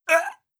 16. Damage Grunt (Male).wav